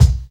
jaydeetribekick.wav